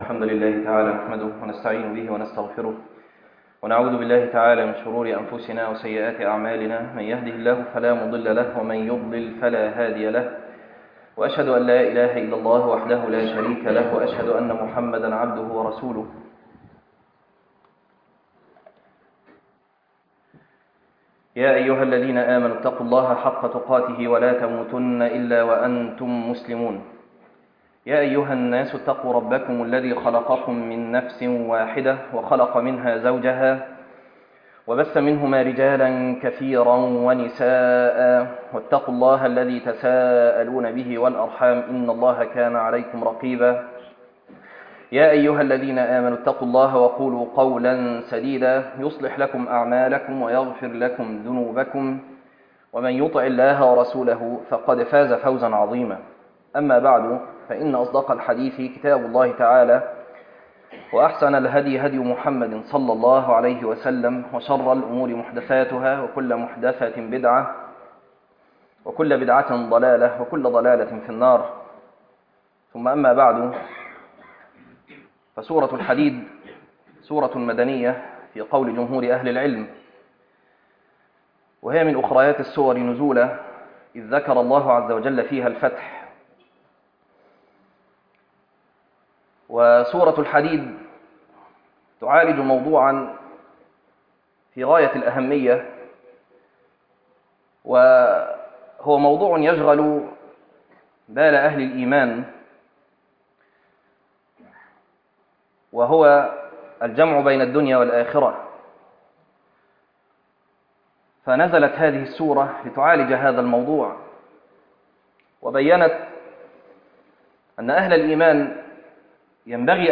تفاصيل المادة عنوان المادة تأملات في سورة الحديد - خطبة تاريخ التحميل الأثنين 13 ابريل 2026 مـ حجم المادة 13.77 ميجا بايت عدد الزيارات 12 زيارة عدد مرات الحفظ 6 مرة إستماع المادة حفظ المادة اضف تعليقك أرسل لصديق